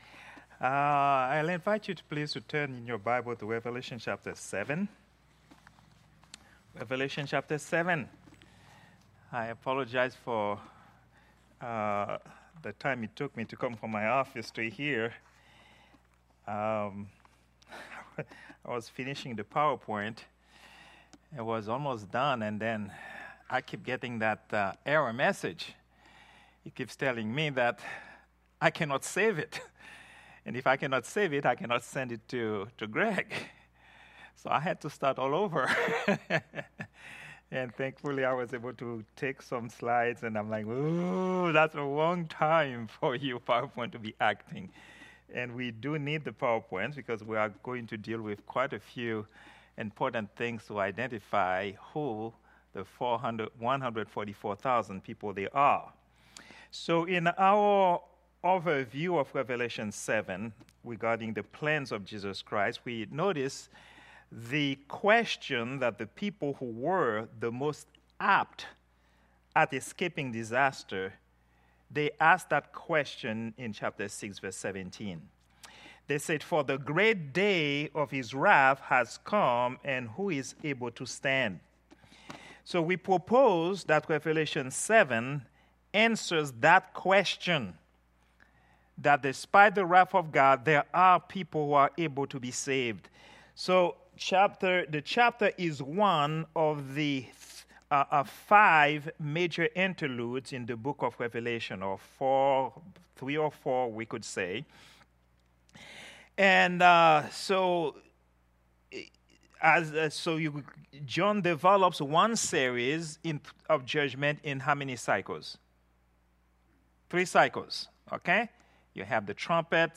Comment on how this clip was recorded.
Prayer_Meeting_07_31_2024.mp3